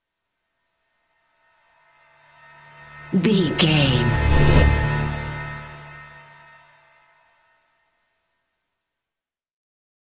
Aeolian/Minor
D
synthesiser
percussion